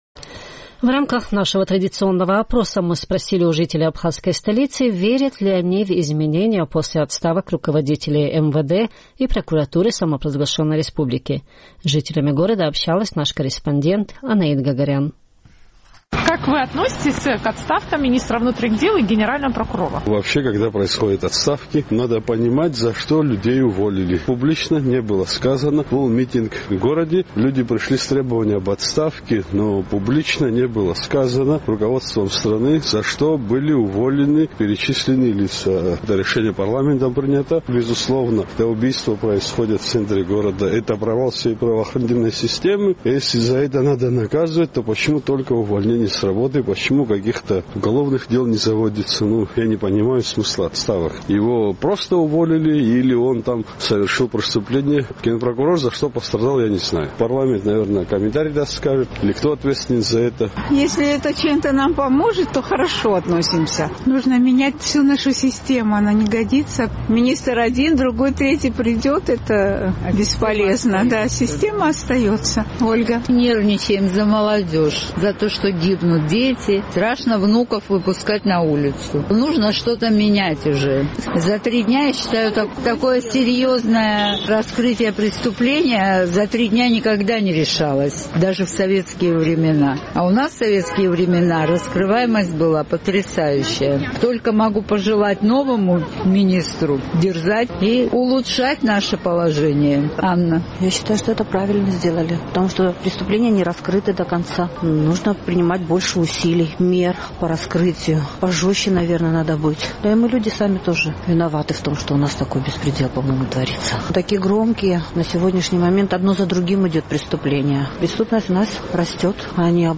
Наш сухумский корреспондент поинтересовалась у местных жителей, как они относятся к отставкам министра внутренних дел и генерального прокурора.